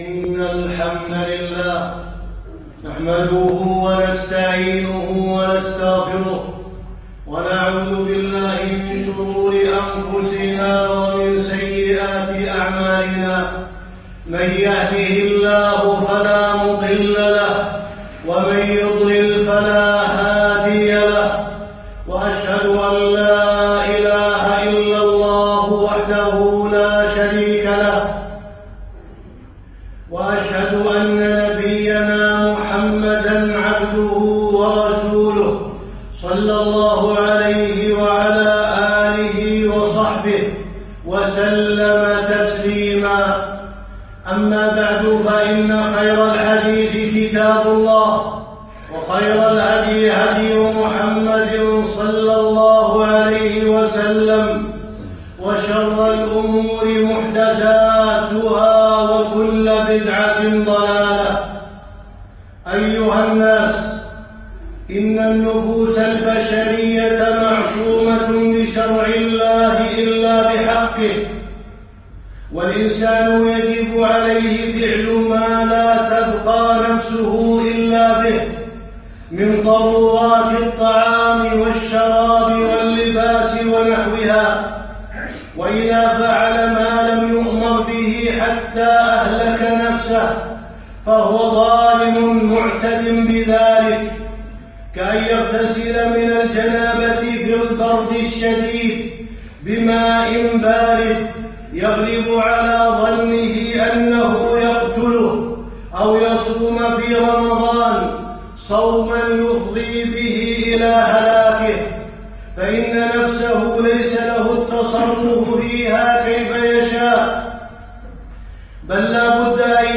يوم الجمعة 20 ذو القعدة الموافق 4 9 2015 مسجد مرضي الراجحي اشبيليا
جريمة الانتحار-خطبة